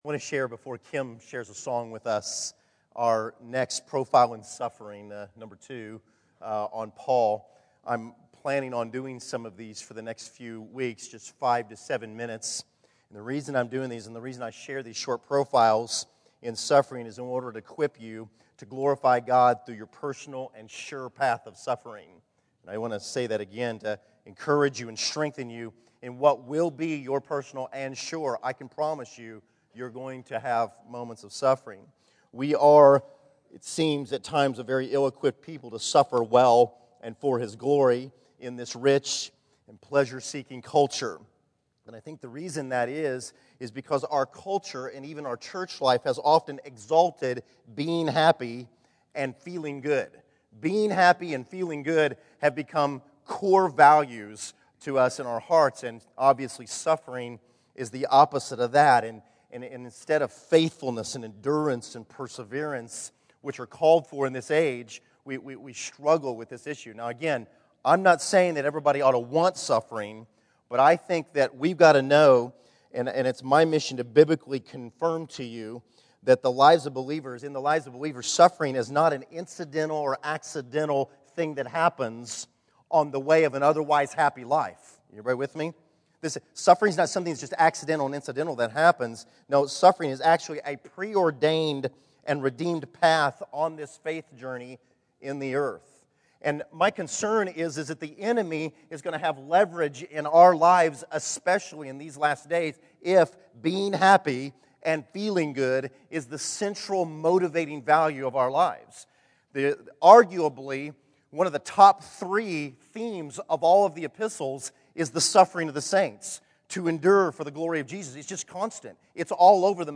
Category: Scripture Teachings | Location: El Dorado Back to the Resource Library Paul suffered more than us all but still proclaimed that it was all worth it.